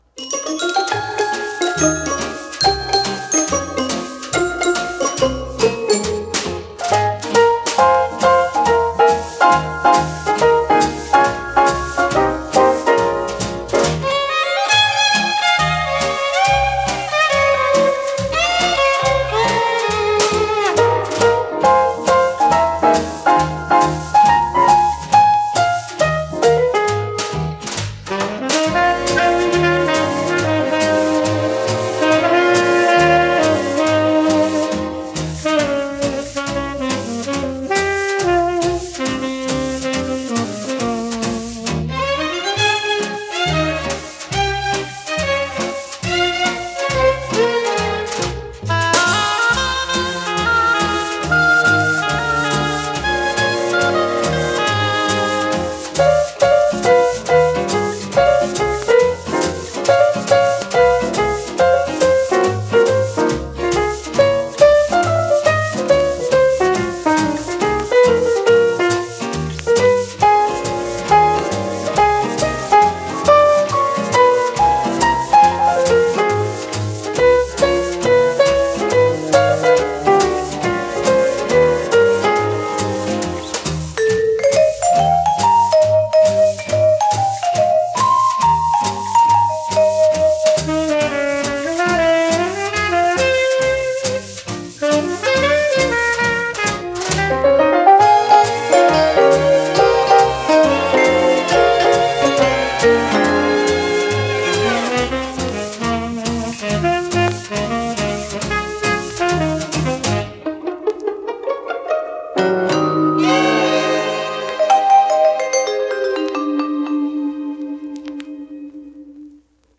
Son: mono / stéréo